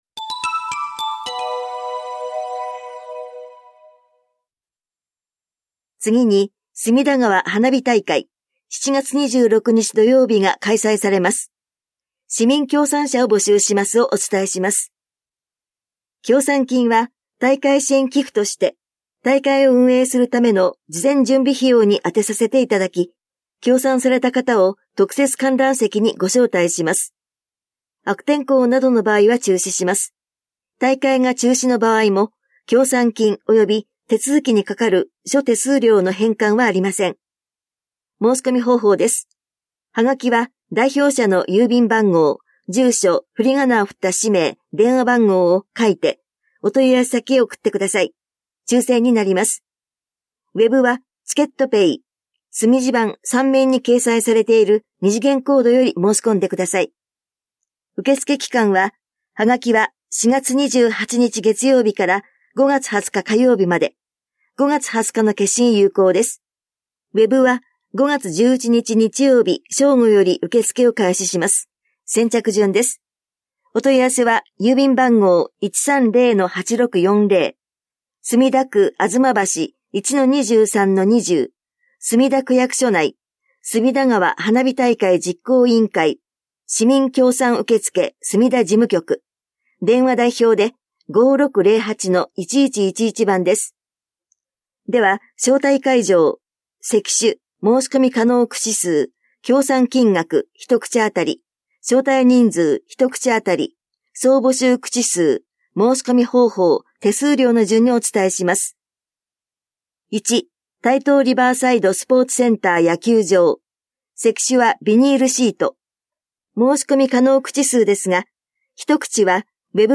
広報「たいとう」令和7年4月20日号の音声読み上げデータです。